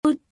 Phoneme_(Commonscript)_(Accent_0)_(21)_(Female).mp3(file size: 24 KB, MIME type: audio/mpeg)
Audio of the phoneme for Commonscript letter 21 (pronounced by female).